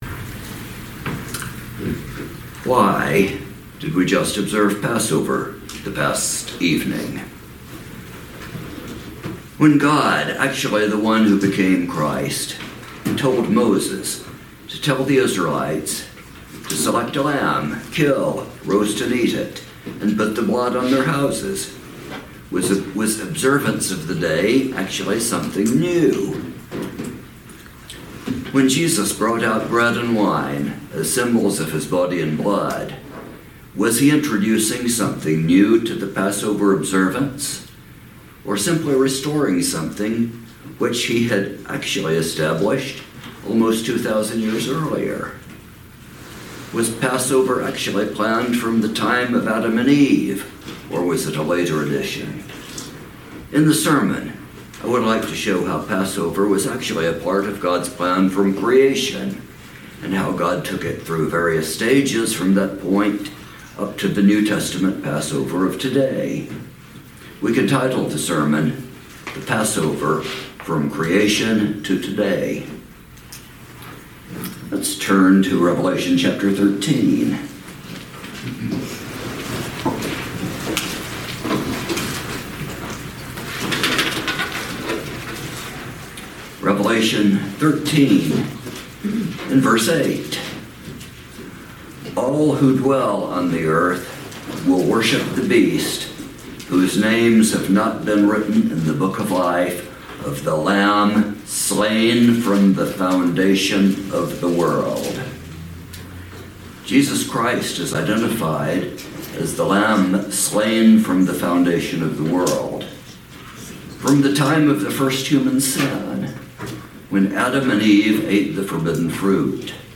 Passover observance has been a part of God’s plan from the beginning. This sermon follows how God took Passover through various stages from the creation of the earth to the New Testament Passover service that we observe today.
Given in Kingsport April 5, 2025